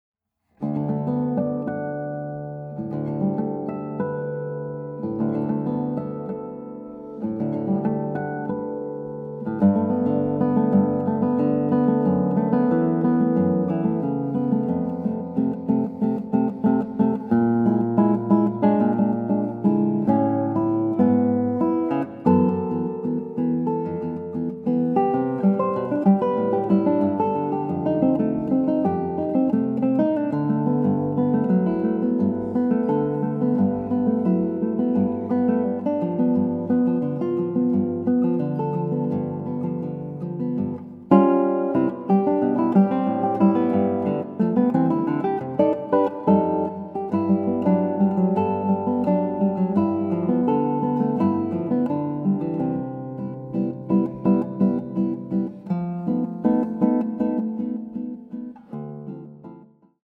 Images become sound – five world premieres for guitar